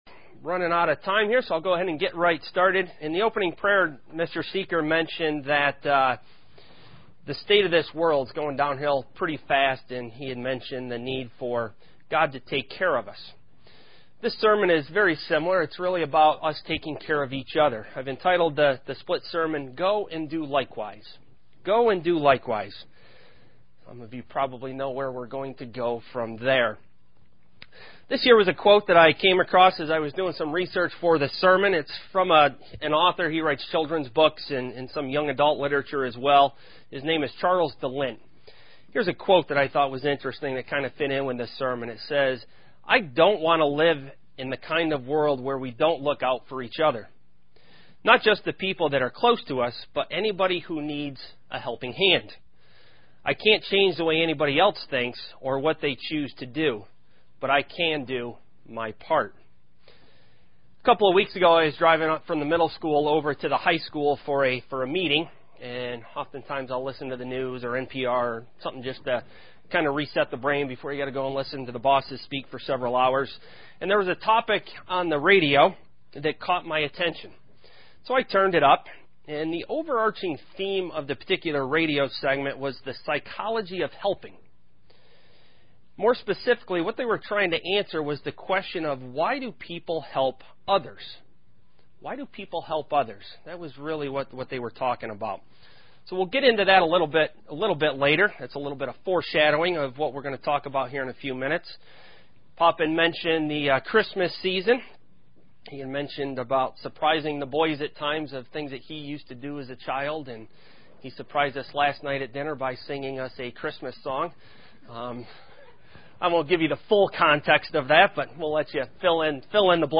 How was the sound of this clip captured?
Given in Elmira, NY